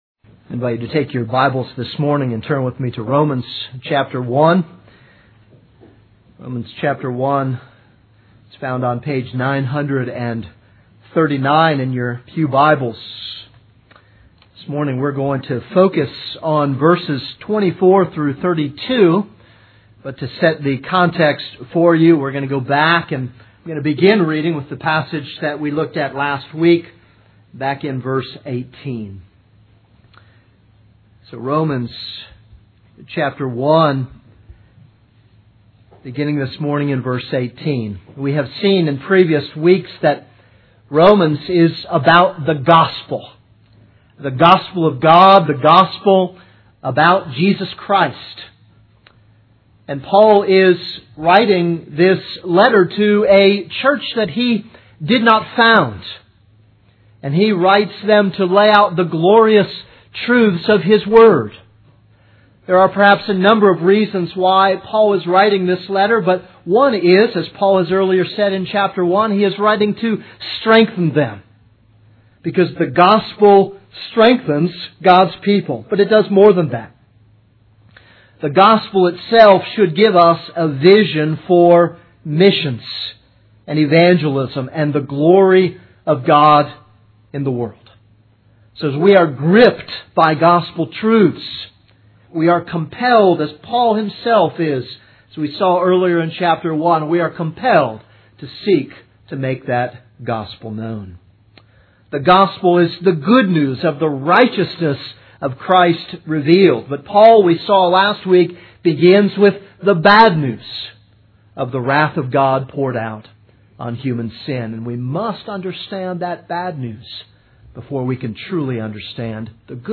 This is a sermon on Romans 1:24-32.